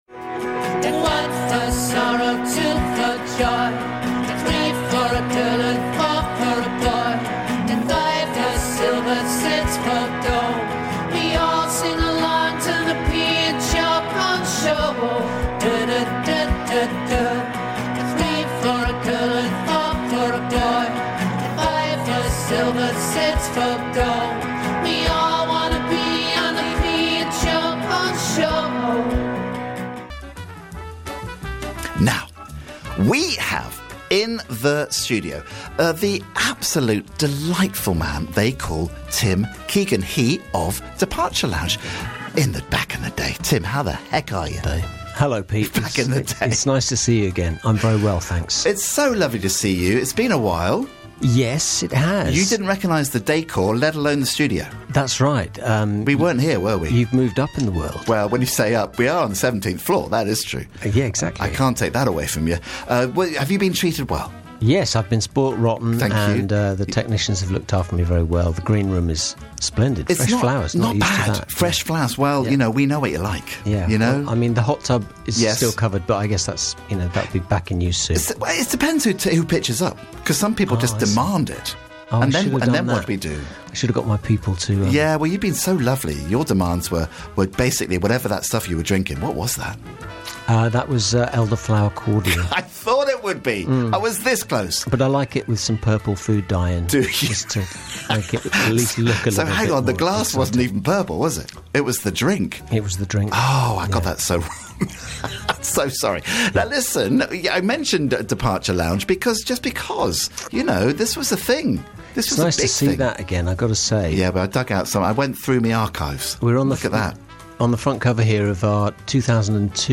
Sounding just as gorgeous as ever.